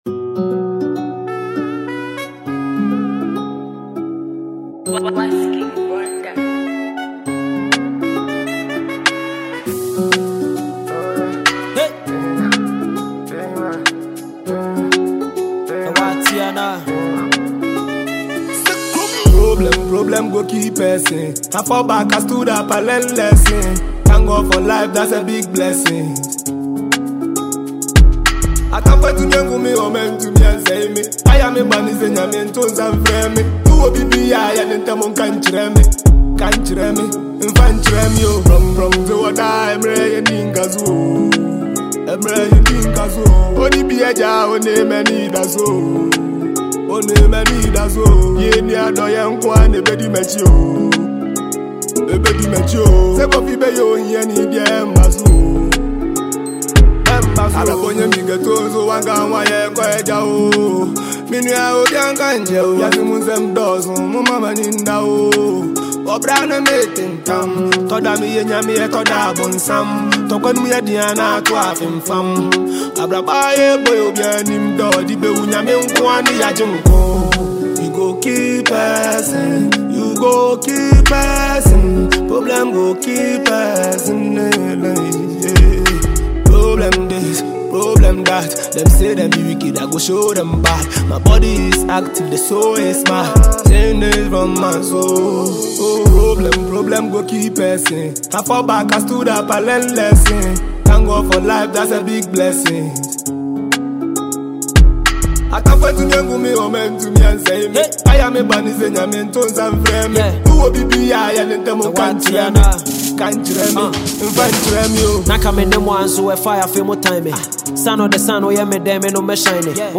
and he features GH rapper
jam tune